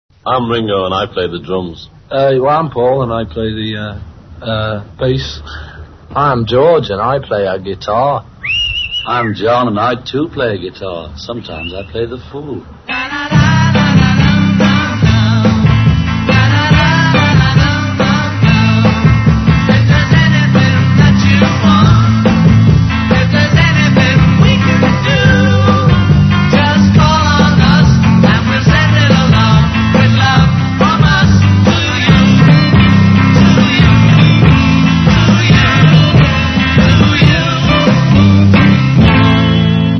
Click Here to DOWNLOAD Recording of Beatles Introducing Themselves(MP3 Format)